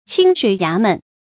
清水衙門 注音： ㄑㄧㄥ ㄕㄨㄟˇ ㄧㄚˊ ㄇㄣˊ 讀音讀法： 意思解釋： 喻沒有油水的機構或地方。